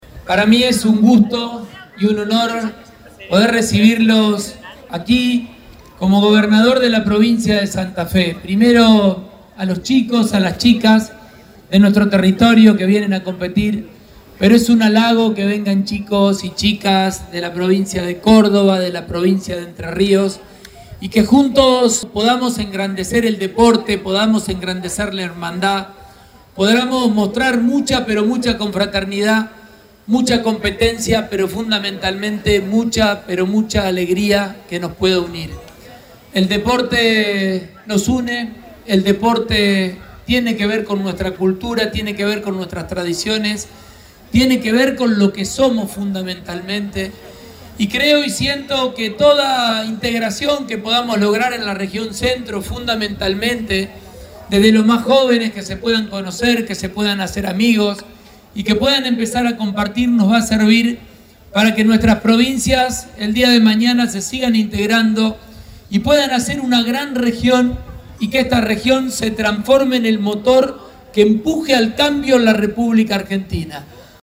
El gobernador Santa Fe encabezó el acto de apertura de la XII edición de los Juegos Deportivos de la Región Centro, oportunidad en la entregó medallas de premiación de algunas disciplinas junto a autoridades de Entre Ríos y Córdoba.
Fragmento del discurso del Gobernador Pullaro